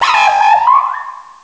pokeemerald / sound / direct_sound_samples / cries / mienfoo.aif